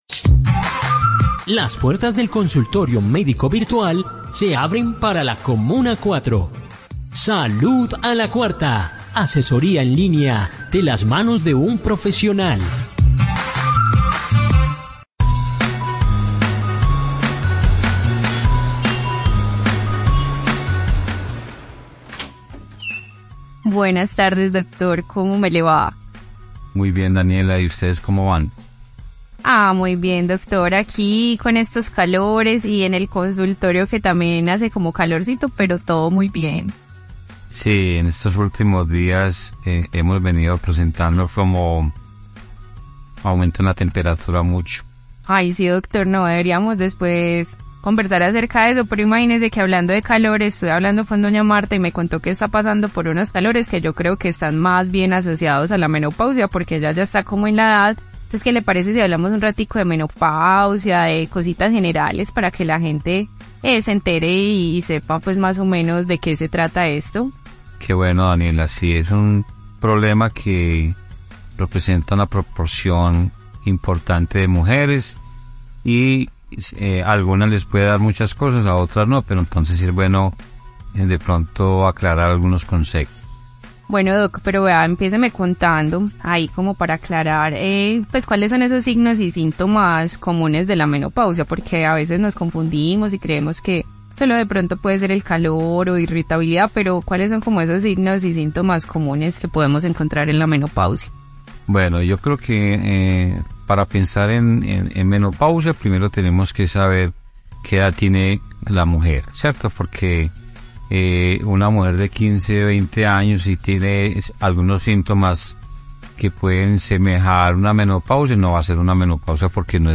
🚫 No te pierdas la oportunidad de obtener consejos prácticos, aclarar dudas y escuchar a un experto en el tema.